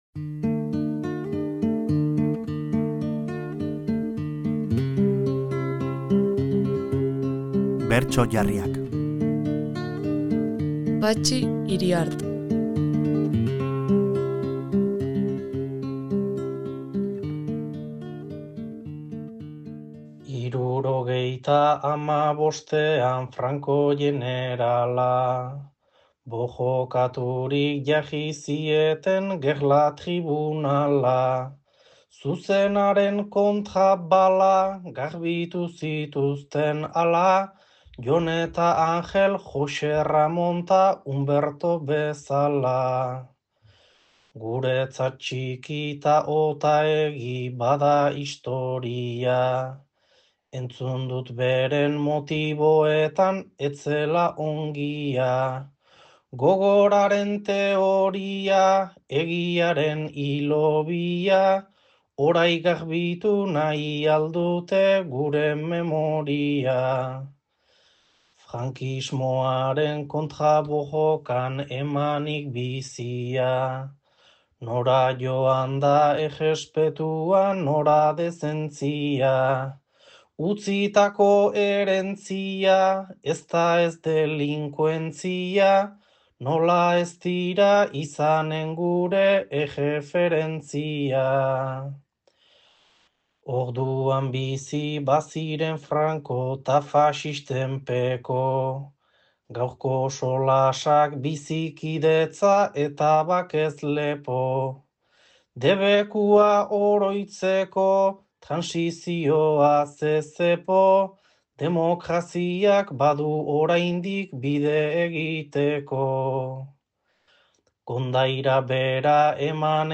Bertso jarriak